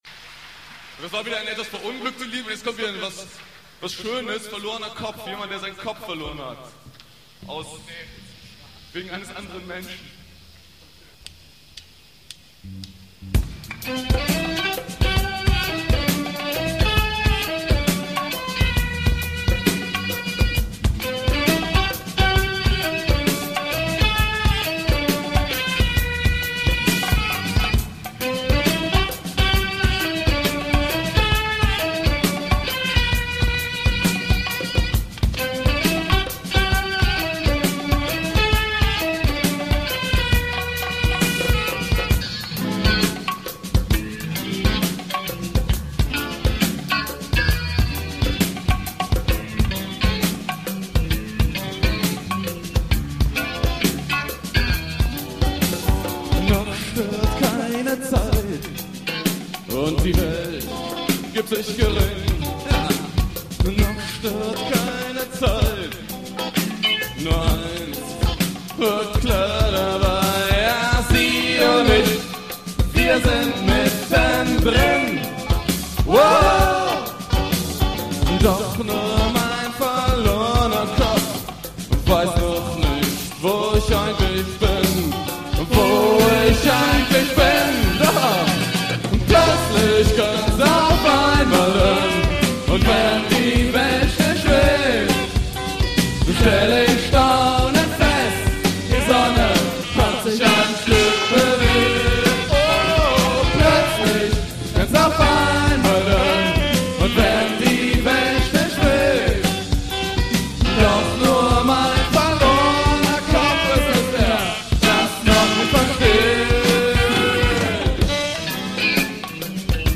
Schlimme Aufnahme, wir haben das damals unprofessionell einfach aus dem Mischpult auf eine eiernde Kassette laufen lassen....